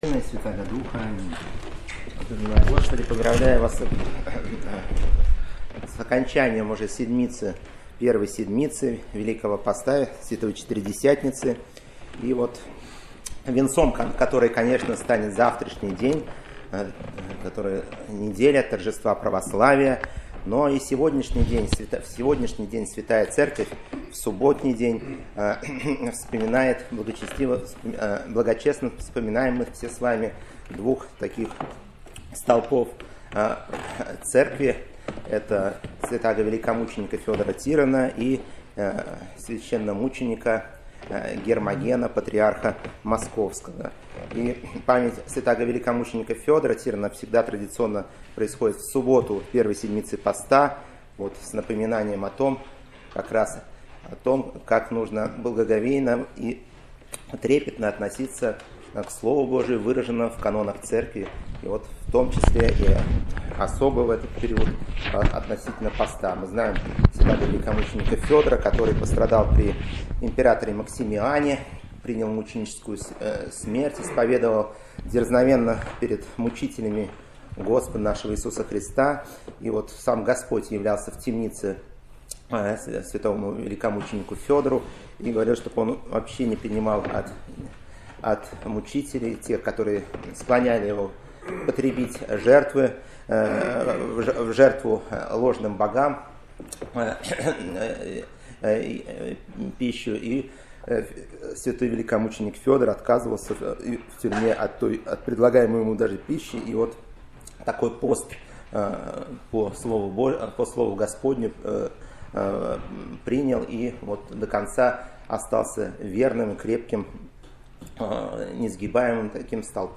Проповеди 2023